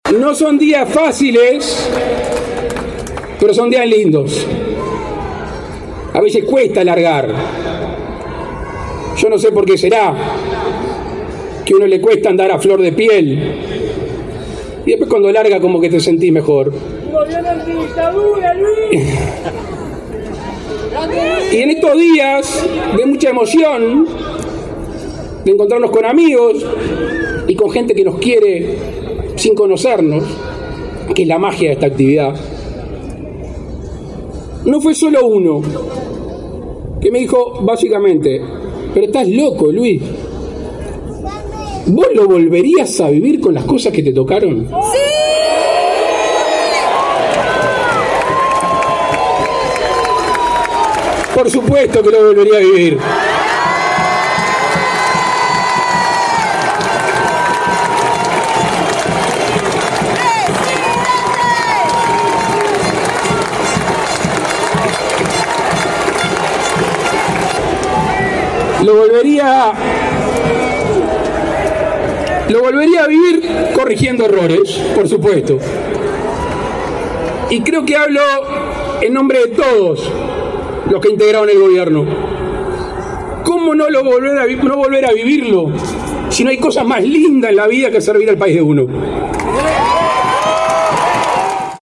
El presidente Luis Lacalle Pou pronunció este viernes en Plaza Independencia su último discurso en el último día de su gestión al frente de la Presidencia de la República.
LACALLE-ULTIMO-DISCURSO.mp3